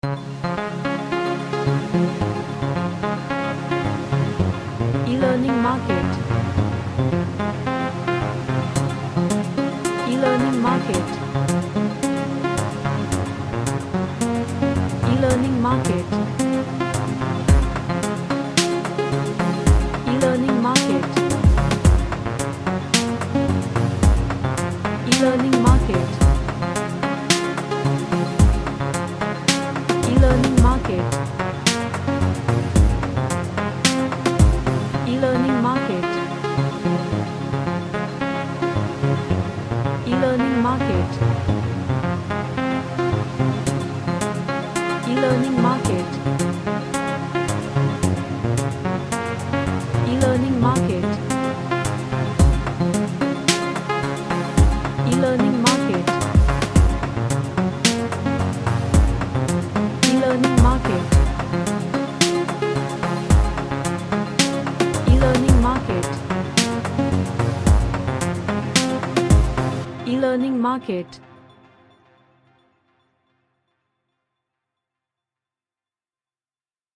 A Dreamy Vibed Track with a groove.
Happy